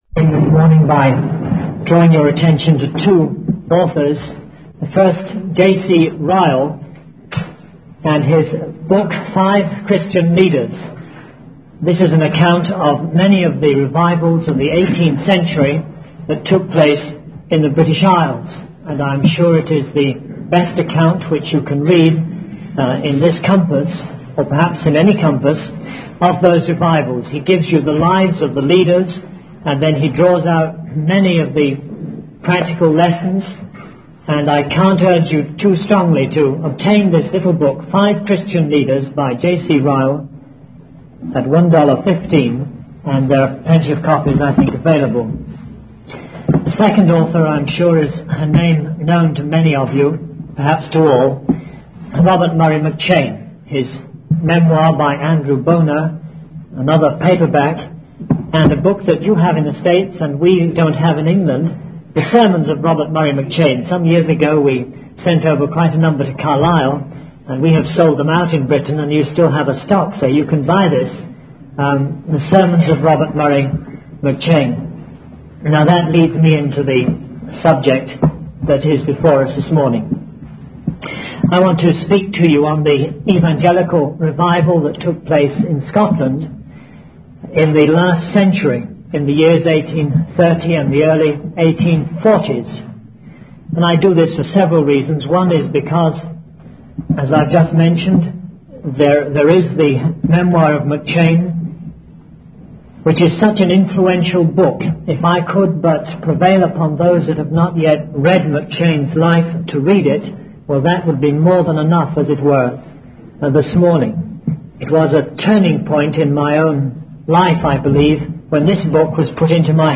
In this sermon, the speaker shares two authors and their books that he recommends.